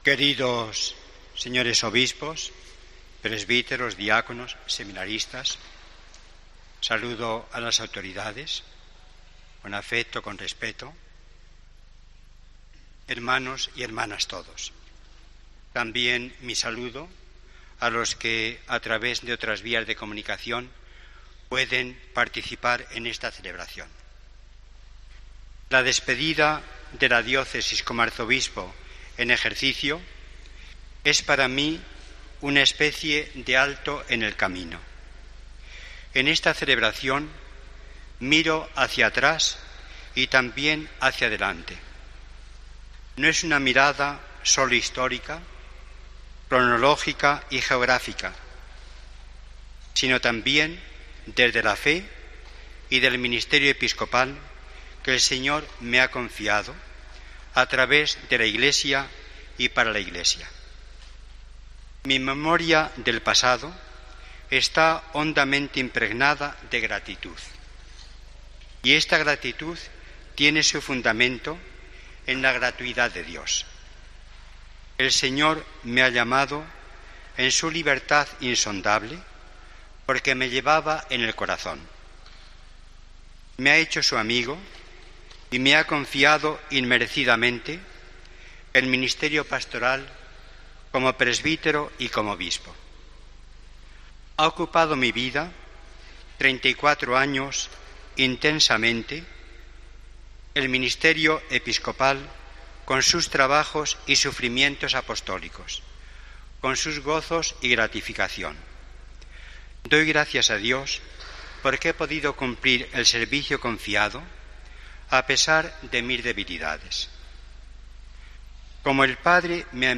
Durante la homilía, Don Ricardo tuvo tiempo para agradecer el acompañamiento de tantas personas: "Conservo recuerdos que se convierten en motivos permanentes de gratitud"
La Iglesia en Valladolid ha despedido con todos los honores a Don Ricardo Blázquez, que ha sido su pastor durante doce años. Con la Misa que ha sido celebrada este sábado en la Catedral se ha dado por concluida su etapa ministerial a la espera de la toma de posesión de Don Luis Argüello, el próximo sábado, como nuevo arzobispo de Valladolid.